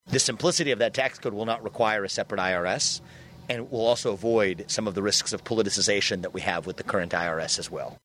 RAMASWAMY MADE HIS COMMENTS DURING A RECENT INTERVIEW WITH RADIO IOWA.